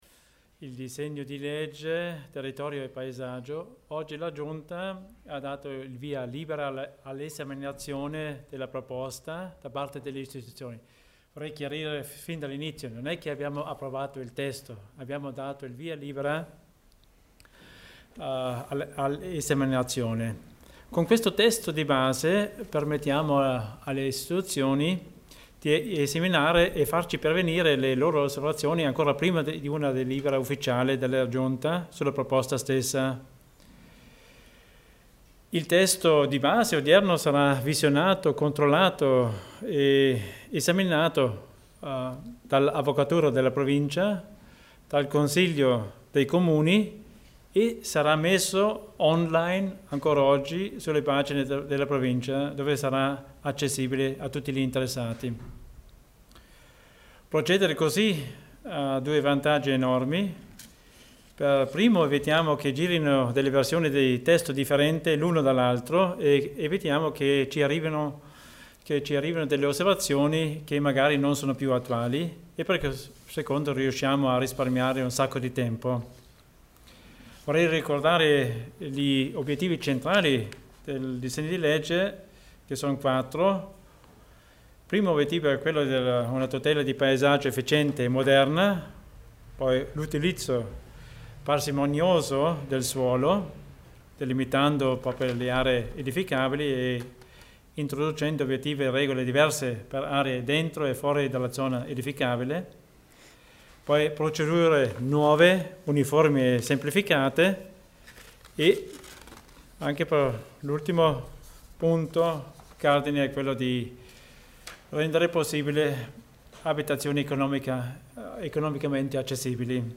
L'Assessore Theiner spiega l'iter per il disegno di legge sul territorio e paesaggio